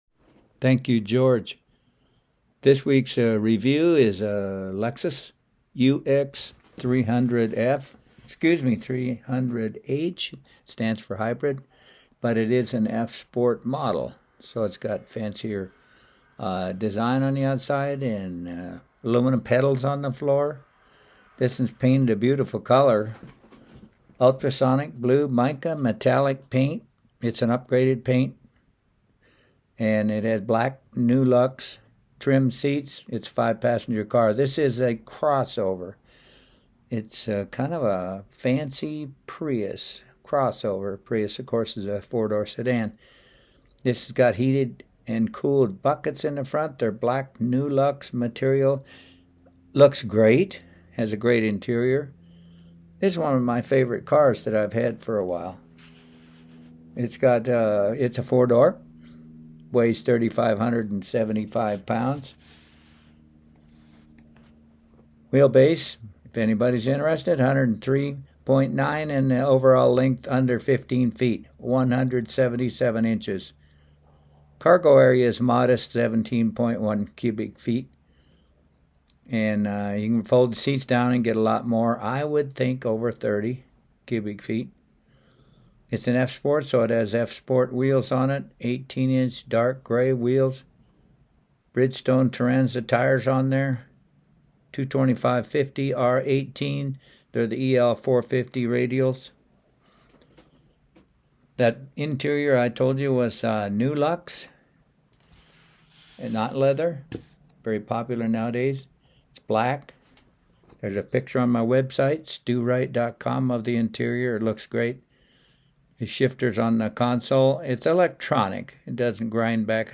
The Lexus review was broadcast on Pirate Radio 104.7 FM in Greeley: